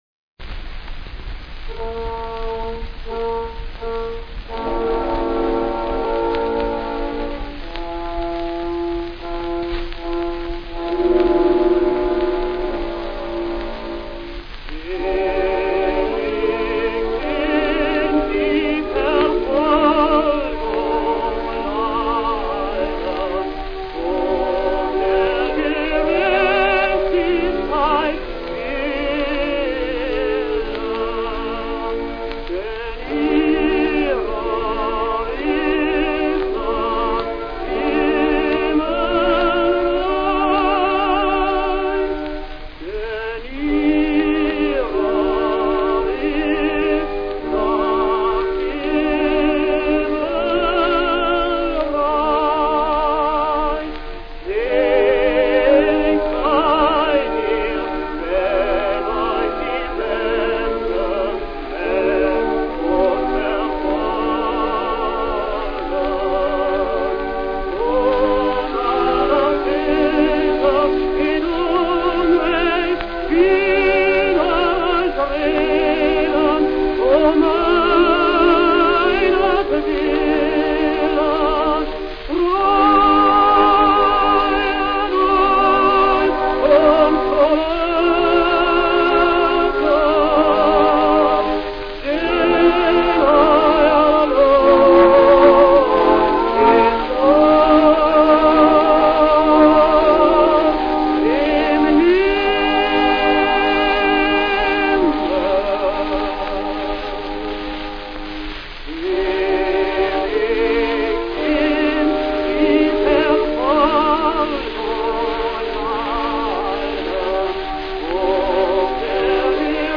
Fritz Vogelstrom (sein Name wurde entfernt - möglicherweise war er Jude ..., andere Beispiele sind hier ) singt hier aus dem "Evangelimann" von Kienzle die Arie "Selig sind, die Verfolgung leiden"